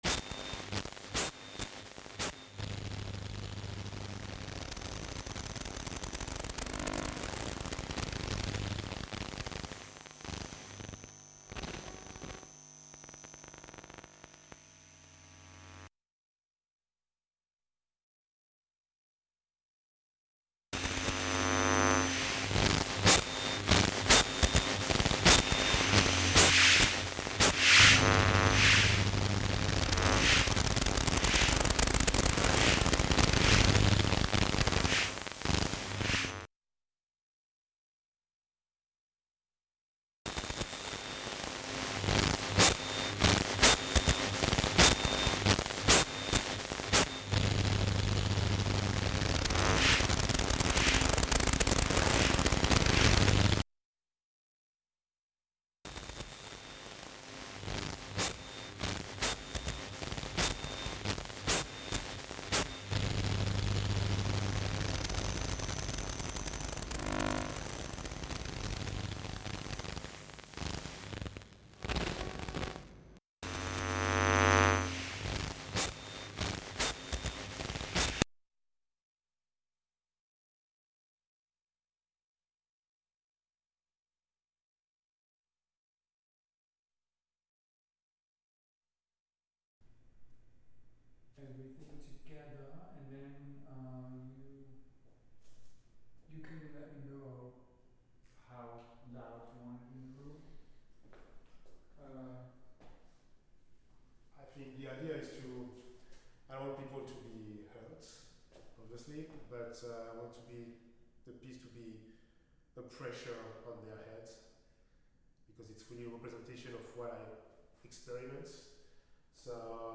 Live from New Ear Inc: New Ear Inc (Audio) Oct 13, 2024 shows Live from New Ear Inc Live from Fridman Gallery Live from New Ear at Fridman Gallery, NYC Play In New Tab (audio/mpeg) Download (audio/mpeg)